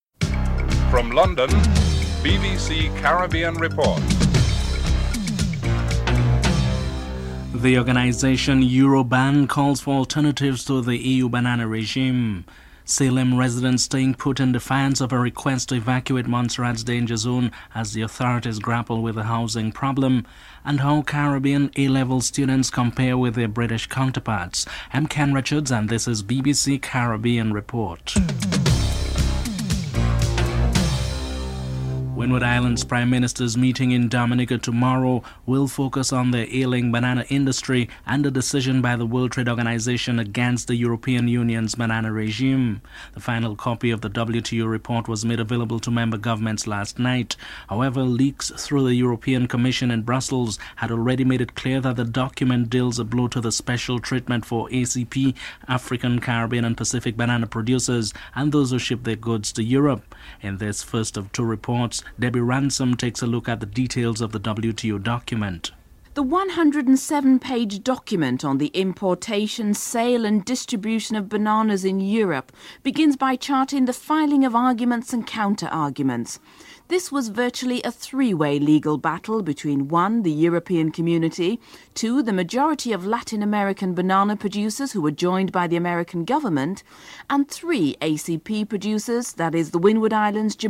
1. Headlines (00:00-00:31)